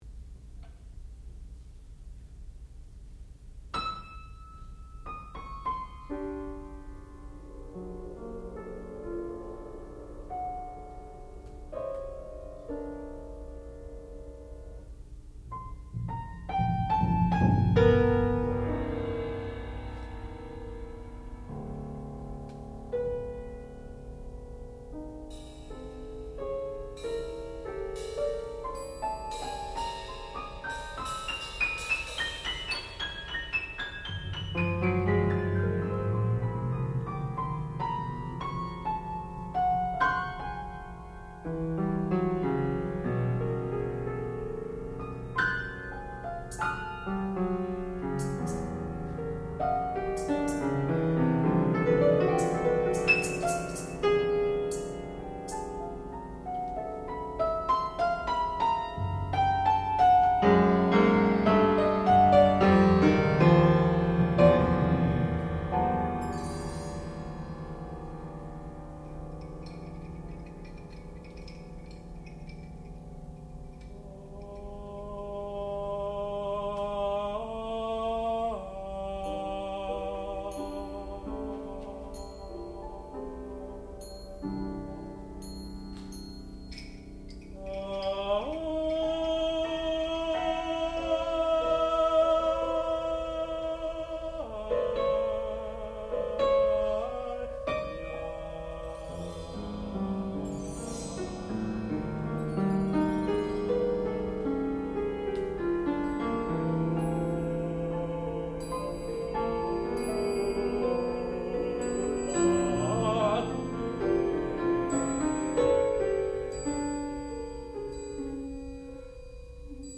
sound recording-musical
classical music
piano
percussion
tenor